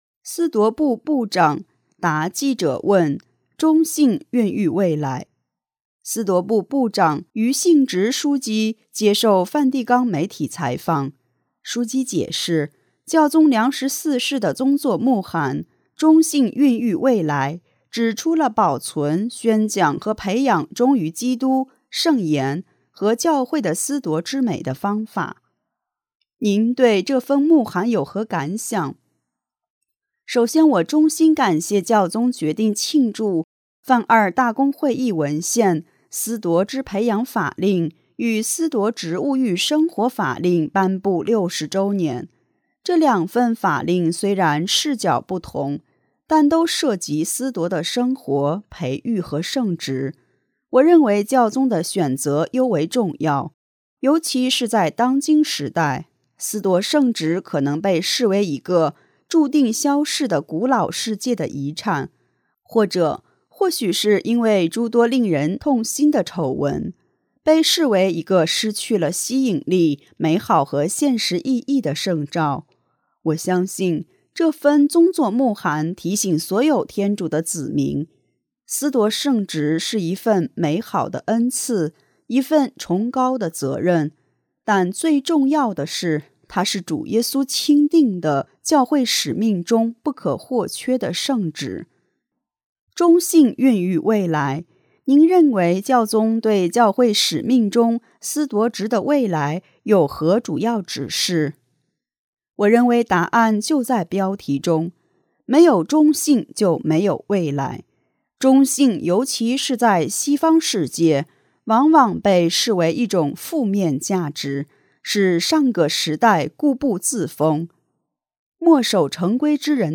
司铎部部长俞兴植枢机接受梵蒂冈媒体采访。枢机解释，教宗良十四世的宗座牧函《忠信孕育未来》（Una fedeltà che genera futuro），指出了保存、宣讲和培养忠于基督、圣言和教会的司铎之美的方法。